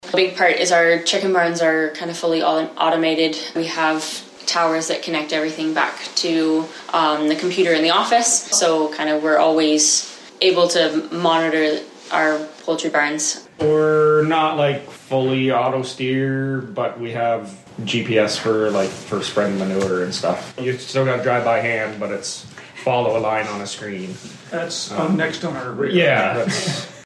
Sitting at the kitchen table, which is where the family meets for coffee twice daily to catch up on what everyone is doing, we talked about the role of technology in their operations. Two main areas benefit from today’s latest tech.